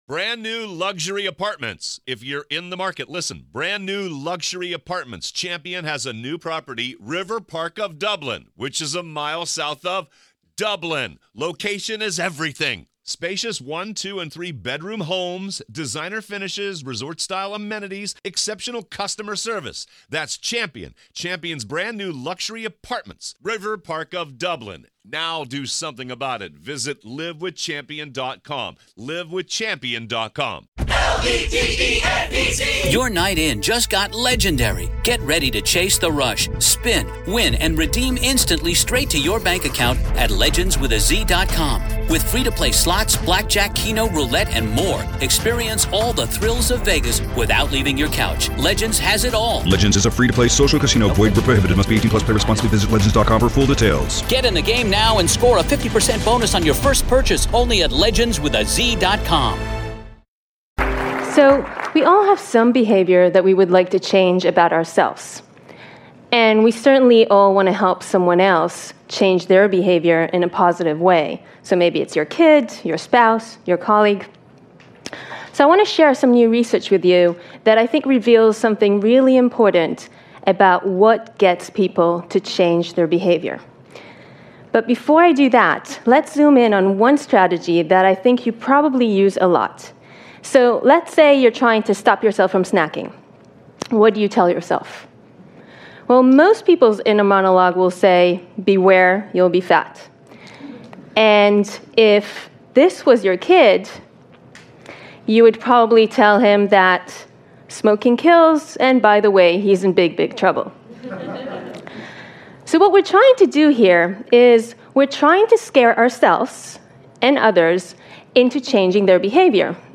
Speaker: Tali Sharot